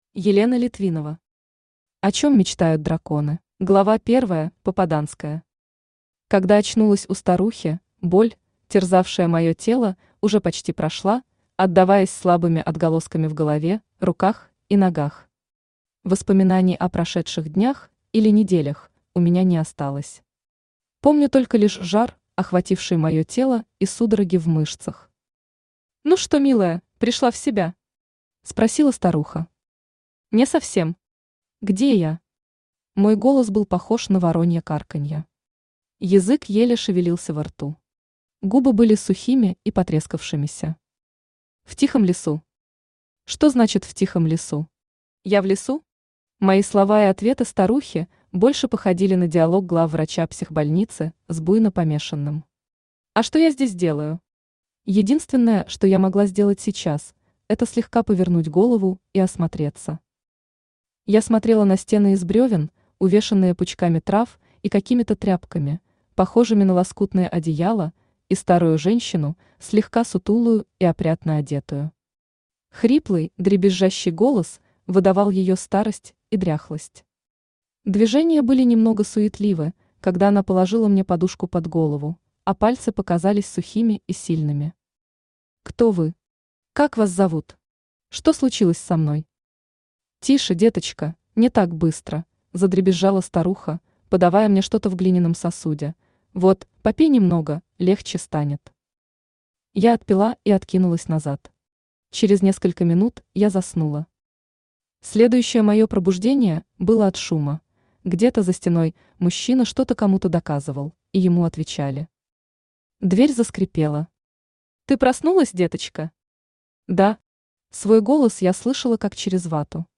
Аудиокнига О чём мечтают драконы | Библиотека аудиокниг
Aудиокнига О чём мечтают драконы Автор Елена Литвинова Читает аудиокнигу Авточтец ЛитРес.